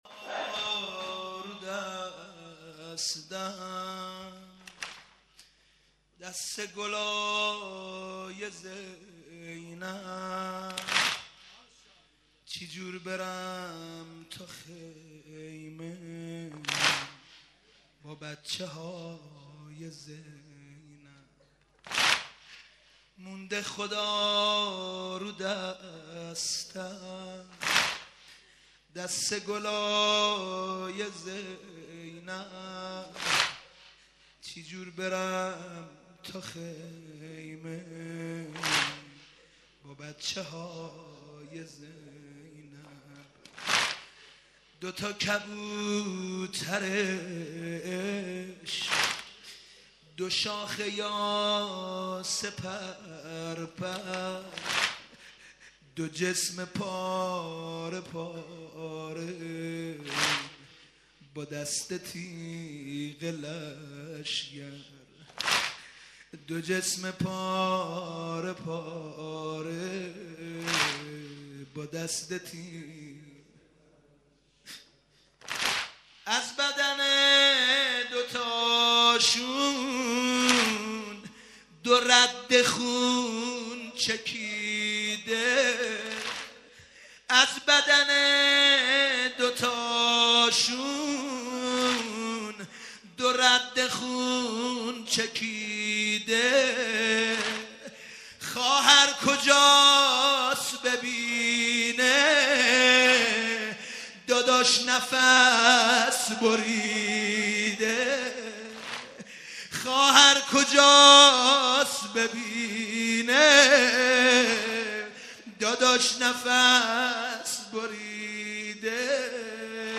شور، زمزمه